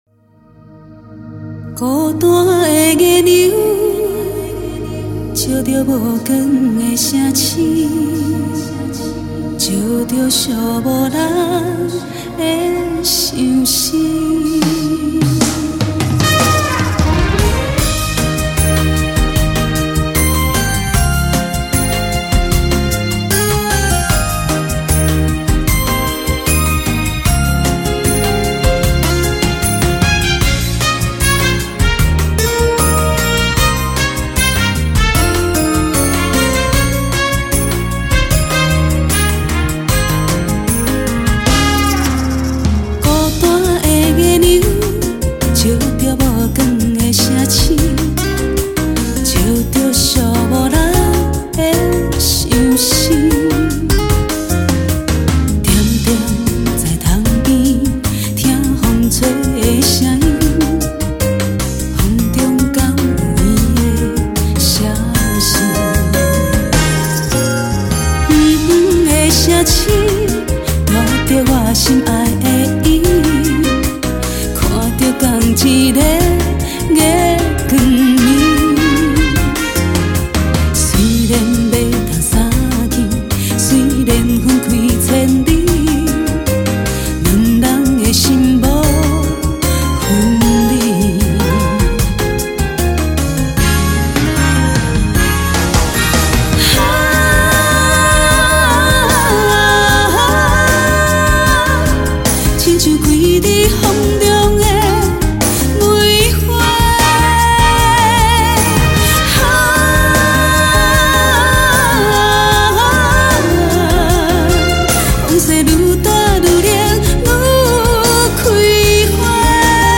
拉丁森巴风情